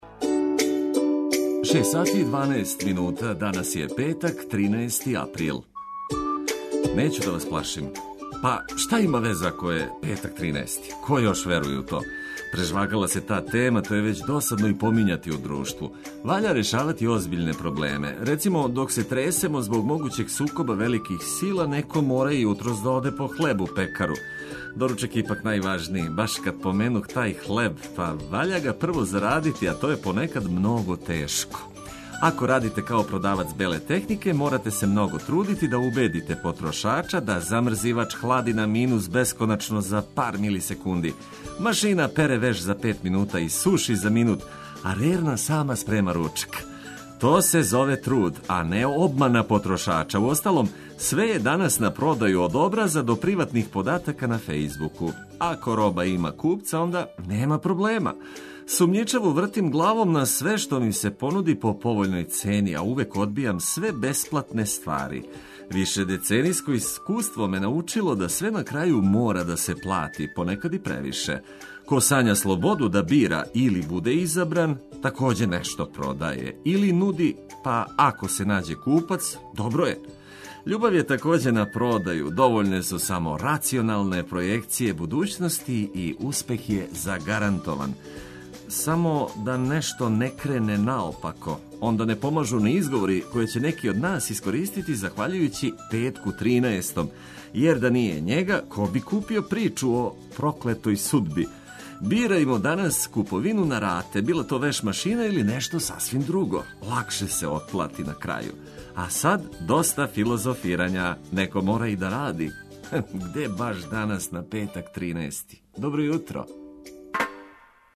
Уз велике хитове и актуелне информације дочекајмо нови дан.